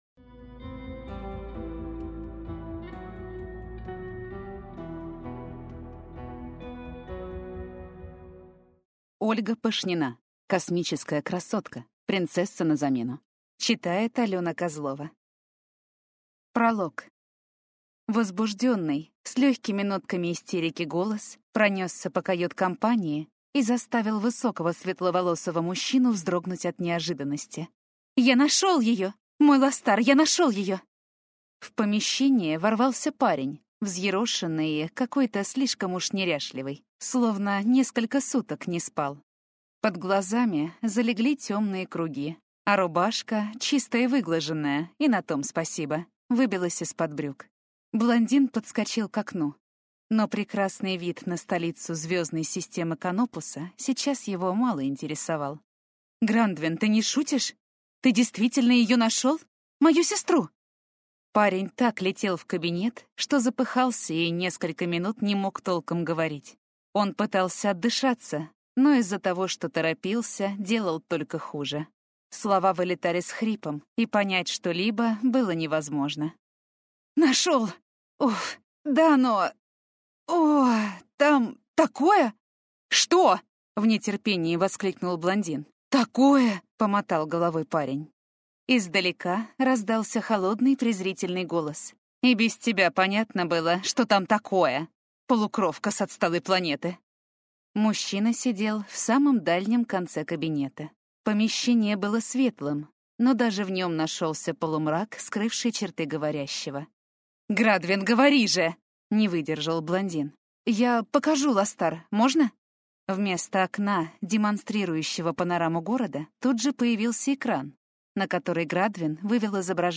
Аудиокнига Космическая красотка.